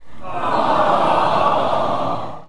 Aaah